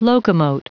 Prononciation du mot locomote en anglais (fichier audio)
Prononciation du mot : locomote